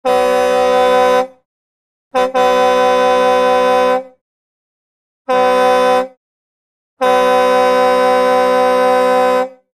Звуки клаксона
Гудок клаксона грузовика или фуры